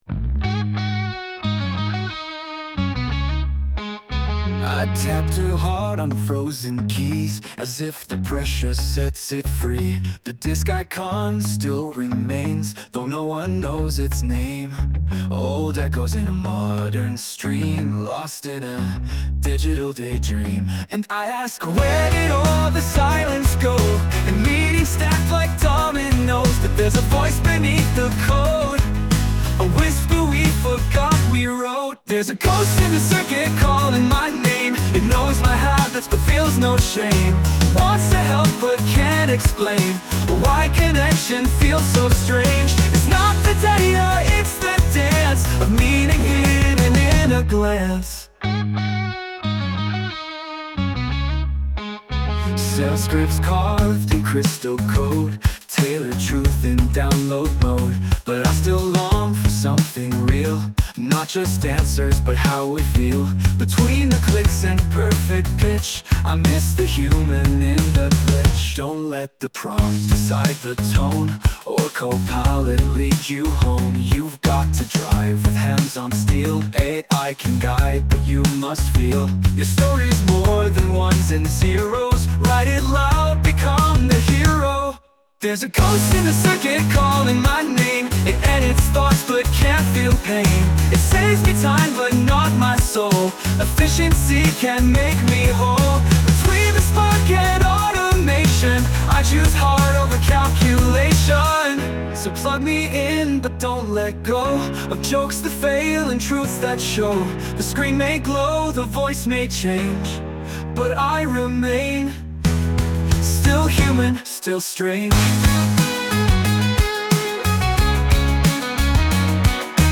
Dit lied is volledig met AI gegenereerd. De teksten zijn afkomstig van de interviews van aflevering 5